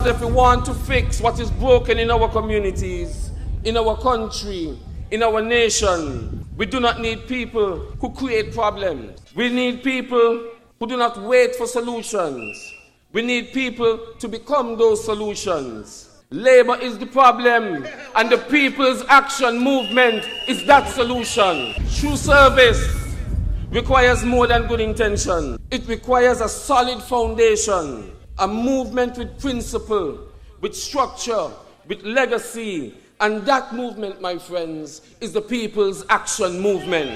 During its annual convention on June 1st, 2025, the People’s Action Movement (PAM) introduced two new candidates for constituencies #2 and #4.